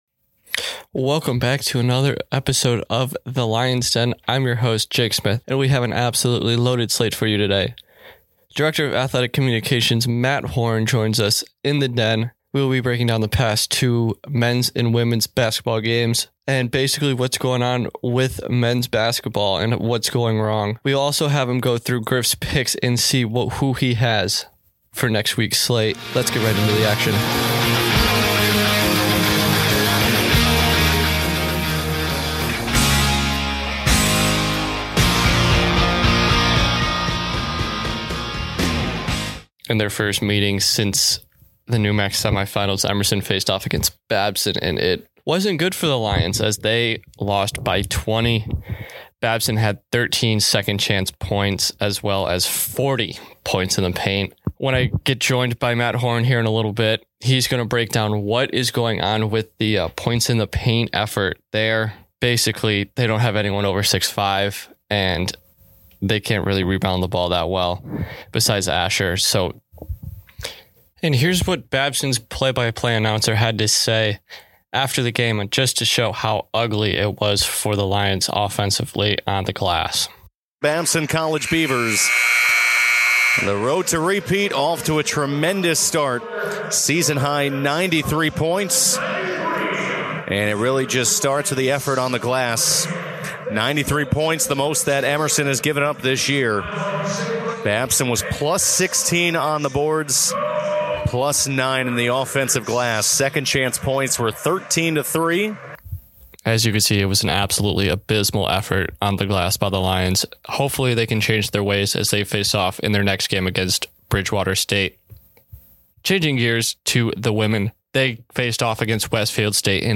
Scriped Intro
Ad Read